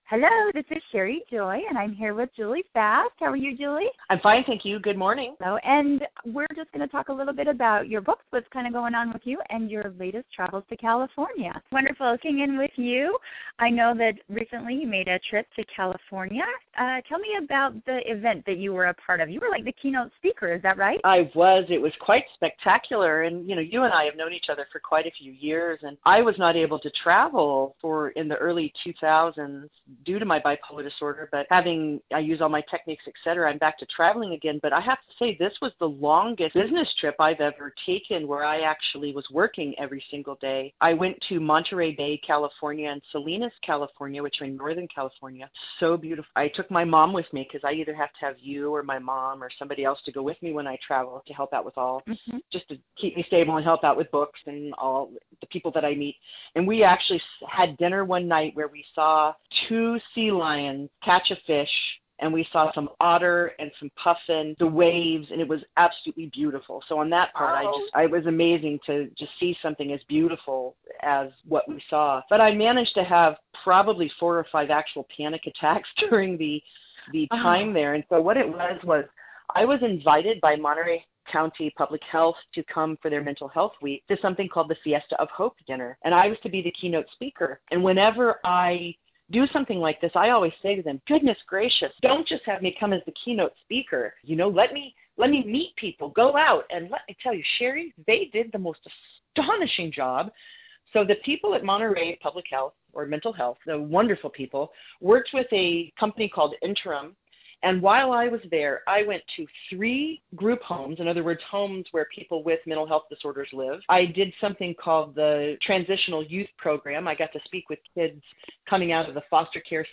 Here is part one of a three part interview I did on the topics of travel, professional speaking, working with Claire Danes on Homeland and my wonderful speaking and training trip to Monterey, California.